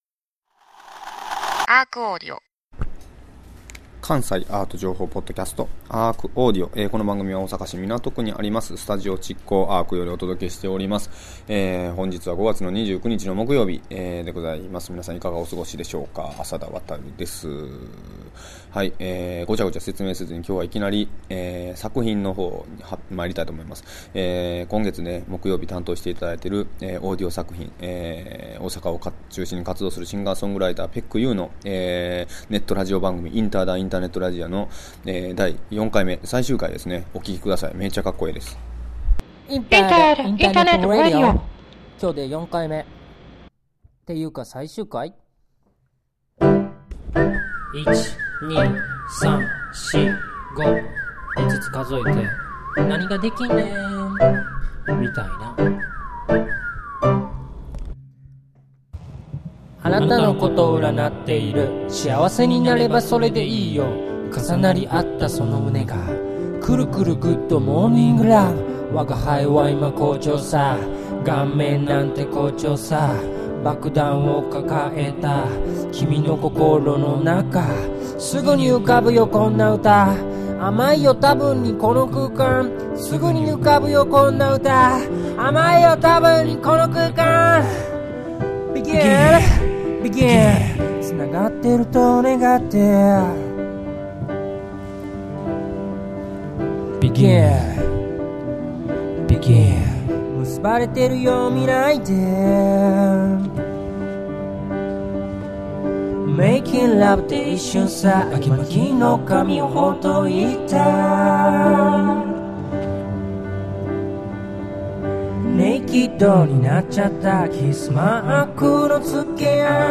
毎週木曜日は関西在住のアーティストによるアートレビューコラム、そして、同じく、関西在住の音楽家を中心にしたアーティストによるオーディオ作品の発表、そして関西イベント情報コーナー！と盛りだくさんの総合エンタメ(!?)番組！